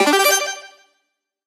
Sound clip from DS Waluigi Pinball in Mario Kart 8 Deluxe
MK8DX_WaluigiPinball-ItemDecide.oga.mp3